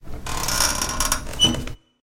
crowbar.ogg.mp3